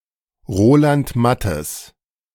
Roland Matthes (German pronunciation: [ˈʁoːlant ˈmatəs],